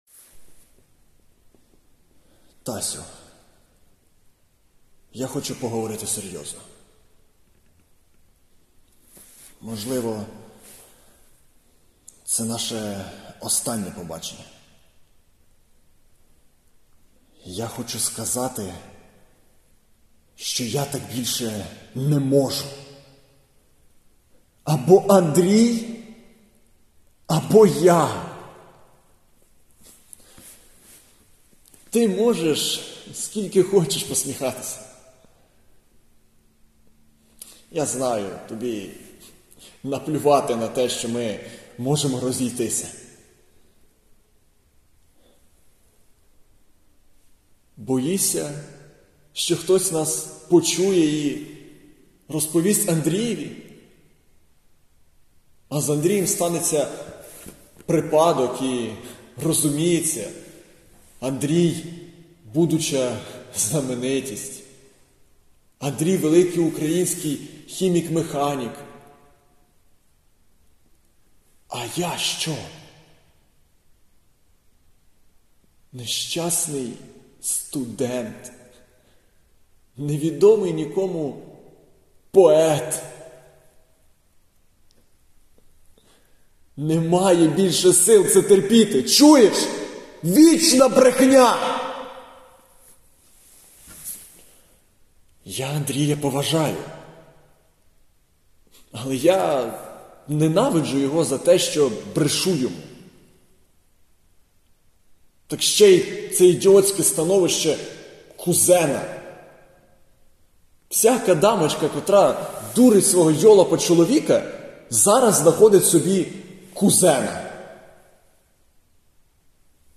Читаю монолог.aac